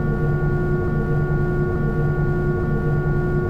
IDG-A32X/Sounds/SASA/V2500/cockpit/v2500-idle.wav at 41640b0aab405391c8a4d8788da387d27aeb3097
Add IAE cockpit sounds
v2500-idle.wav